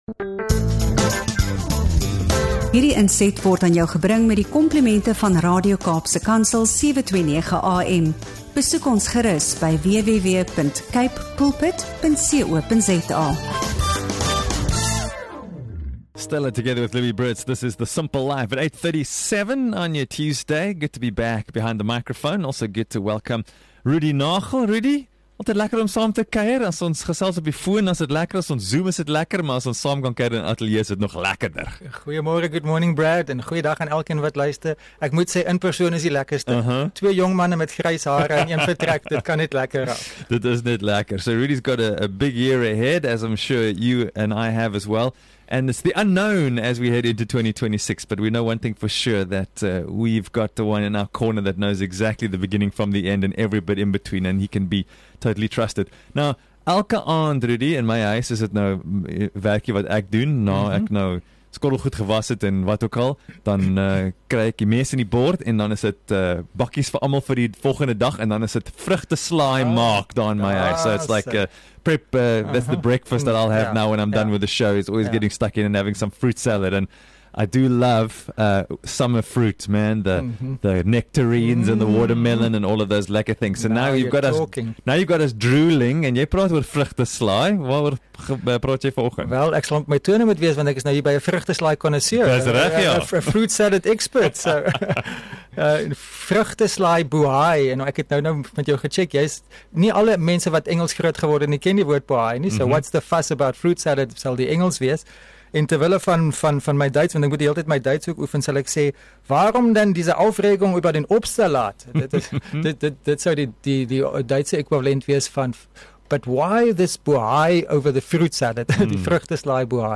In hierdie diep en eerlike gesprek op Sumpel Live